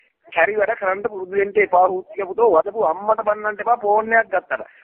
kari wada karanna purudu wenna epa Meme Sound Effect